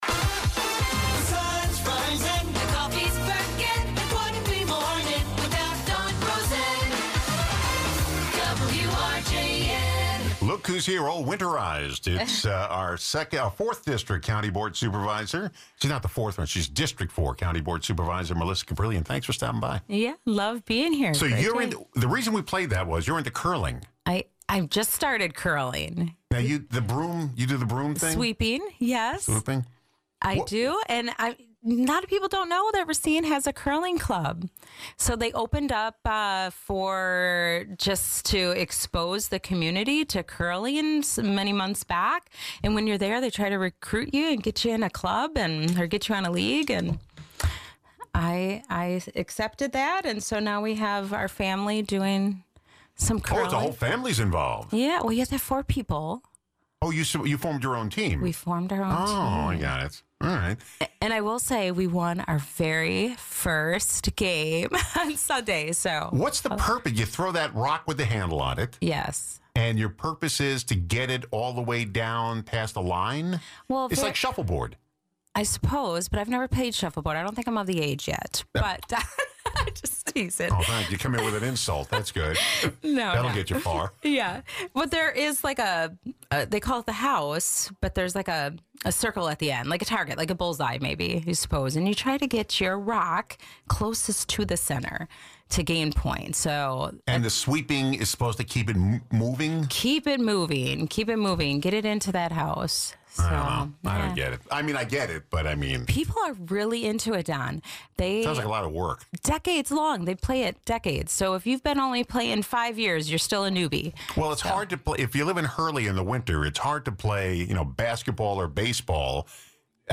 Fourth District County Board Supervisor, Melissa Kaprelian, looks back at 2025 and ahead to 2026.